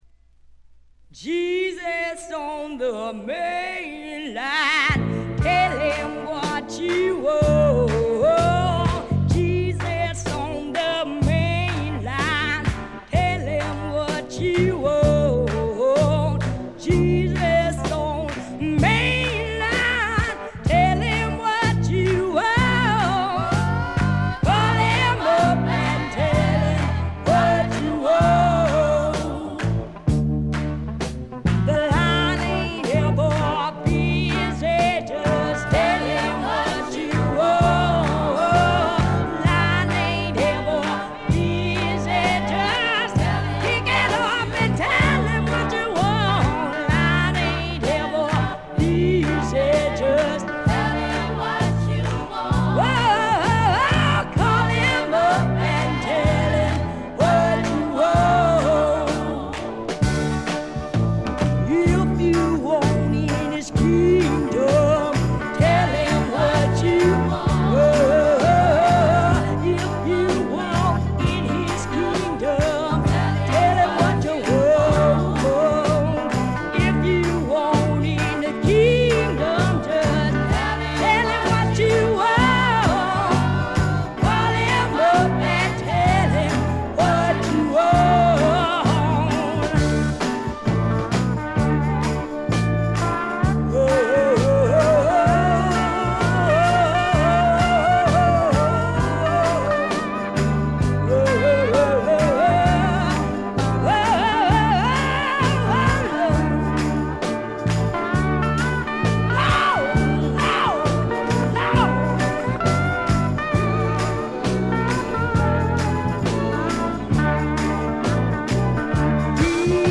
ほとんどノイズ感無し。
超重量級スワンプ名作。
試聴曲は現品からの取り込み音源です。